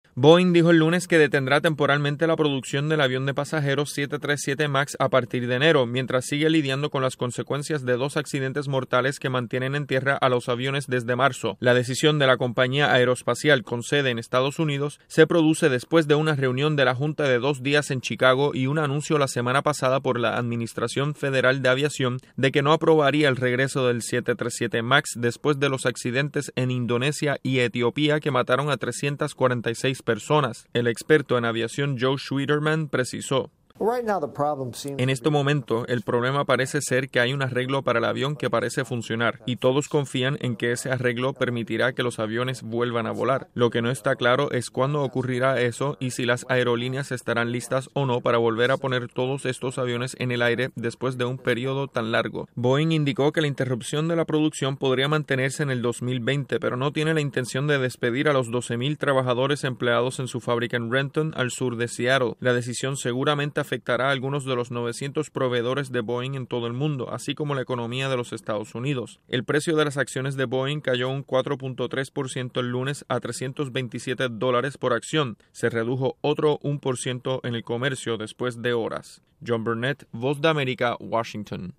La compañía aeroespacial Boeing suspenderá la producción del avión 737 Max en enero. Desde la Voz de América en Washington DC informa